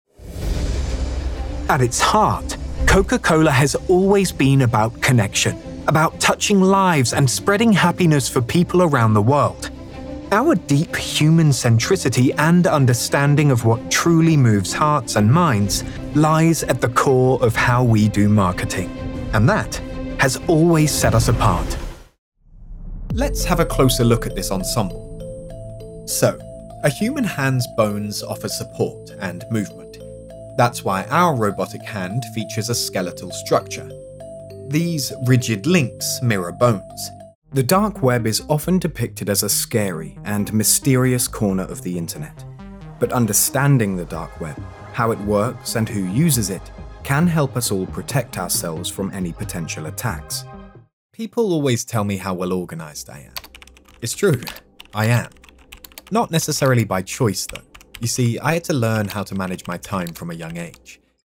Never any Artificial Voices used, unlike other sites.
Male
English (British)
Yng Adult (18-29), Adult (30-50)
E-Learning